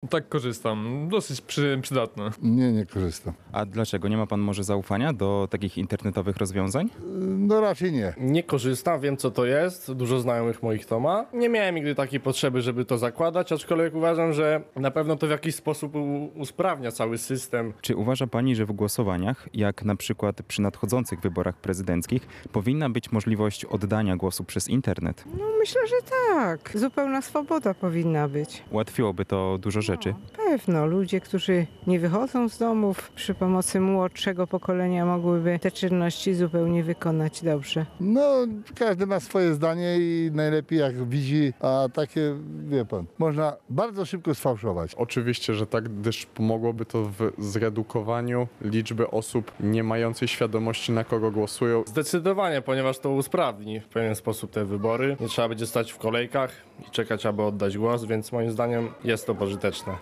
Europejski Dzień e-aktywności Obywatelskiej (sonda)
e-uslugi-sonda.mp3